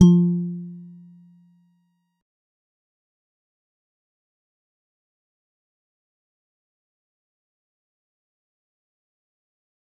G_Musicbox-F3-mf.wav